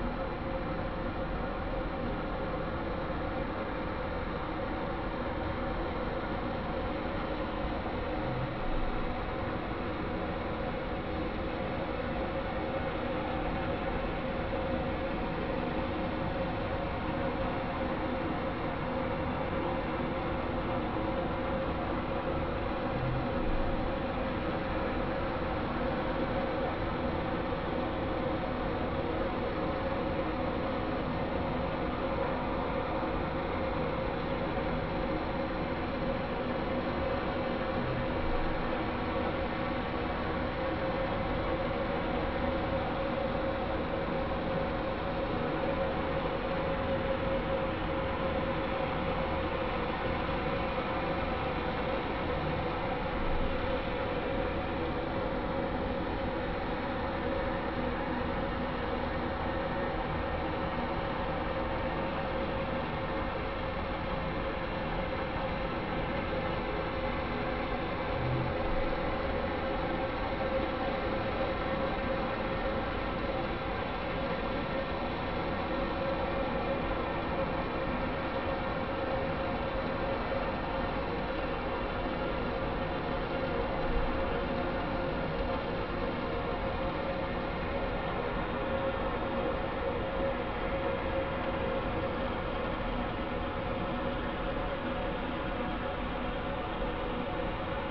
Fin Whale (Balaenoptera physalus)
Recorded in the Mediterranean sea, Cabo San Antonio.
SAMARUC is a research passive acoustic monitoring system designed and build in the UPV for the study of marine mammals and anthropogenic sounds.